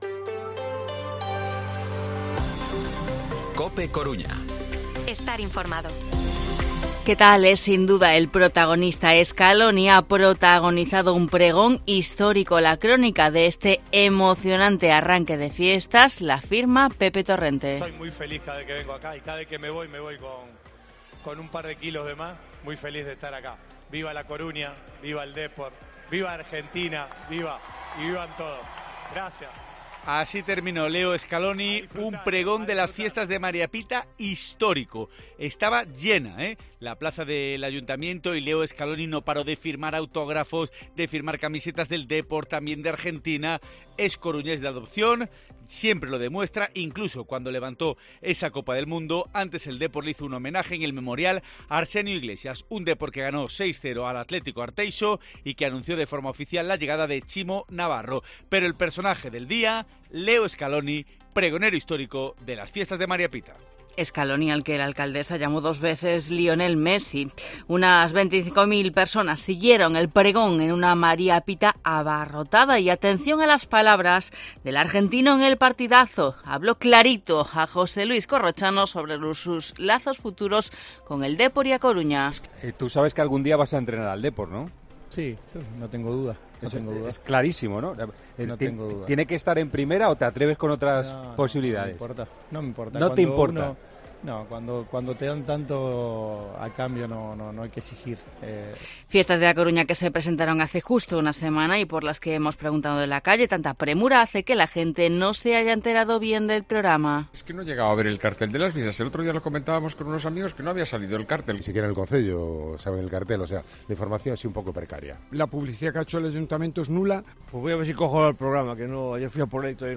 Informativo Mediodía COPE Coruña martes, 2 de agosto de 2023 8:24-8:29